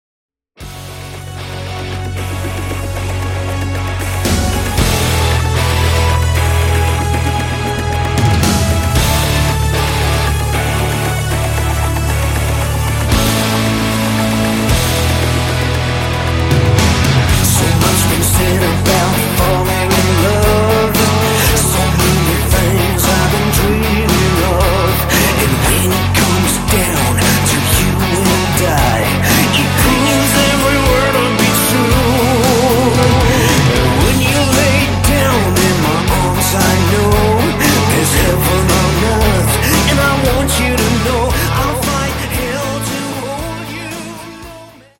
Category: Power AOR / melodic hard rock